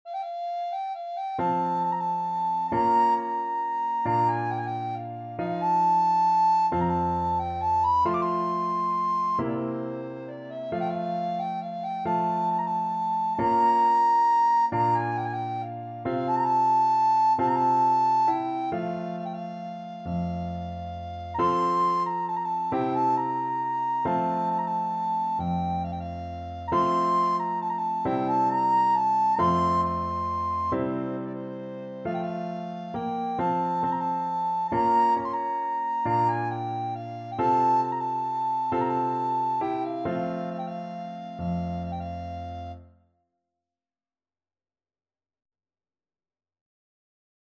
World Trad. Joc In Patru (Romanian Folk Song) Soprano (Descant) Recorder version
Recorder
Traditional Music of unknown author.
F major (Sounding Pitch) (View more F major Music for Recorder )
3/4 (View more 3/4 Music)
One in a bar .=45
romania_joc_in_patru_REC.mp3